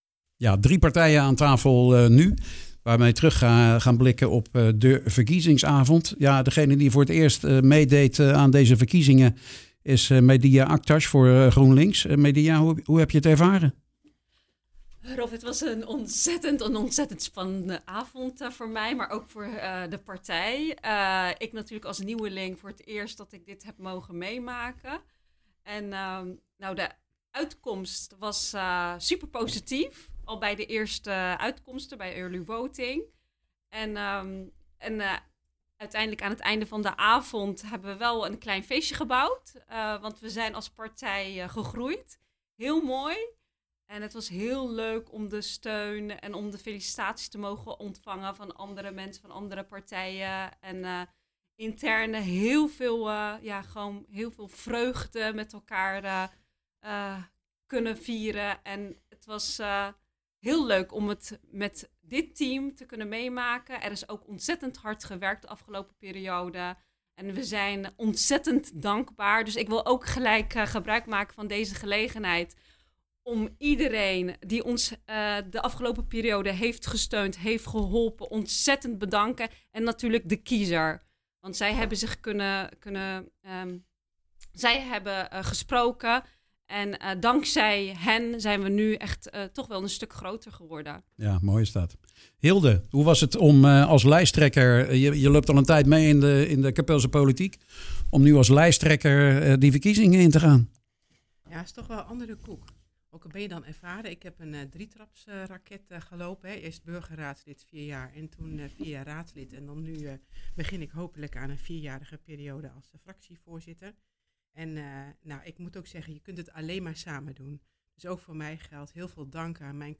ging met (bijna) alle partijen in gesprek.